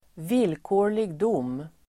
Uttal: [²v'il:kå:r_lig d'om:]